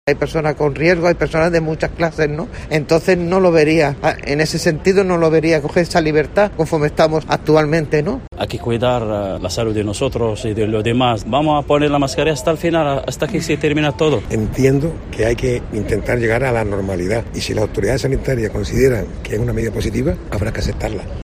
Ciudadanos opinan sobre la mascarilla